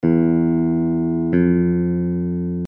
In the diagrams below, we are jumping from a note on an open string (any string will do) to another note on the same string.
Minor Second = ½ step
minor-2nd.mp3